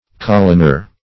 coloner - definition of coloner - synonyms, pronunciation, spelling from Free Dictionary Search Result for " coloner" : The Collaborative International Dictionary of English v.0.48: Coloner \Col"o*ner\, n. A colonist.